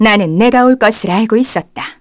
Artemia-vox-dia_02_kr.wav